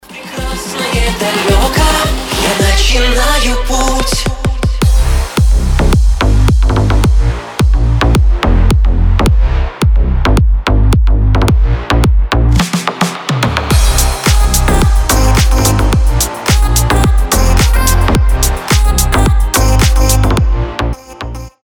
• Качество: 320, Stereo
deep house
Club House
tropical house
ремиксы